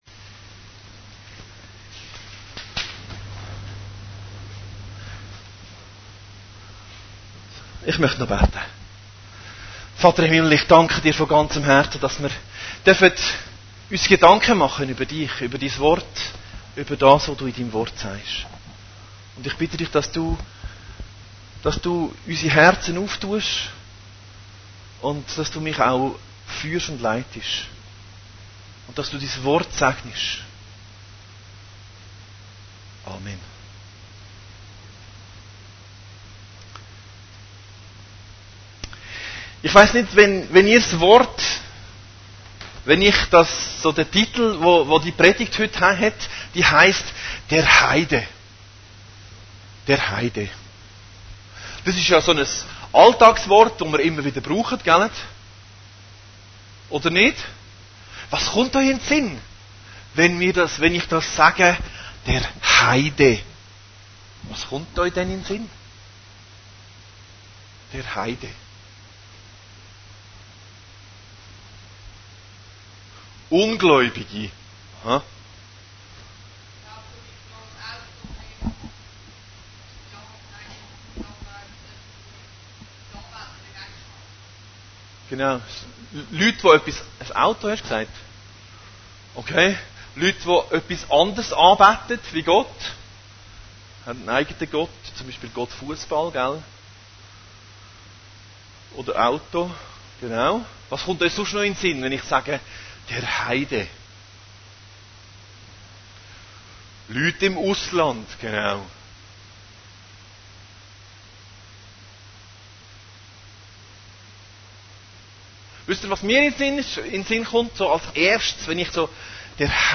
Predigten Heilsarmee Aargau Süd – Der Heide